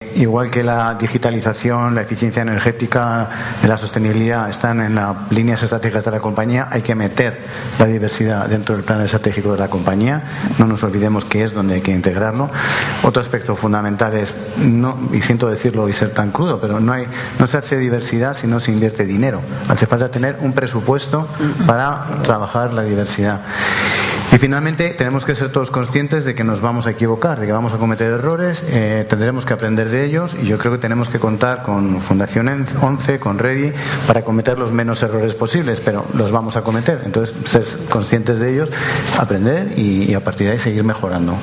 Mesa redonda por la diversidad transversal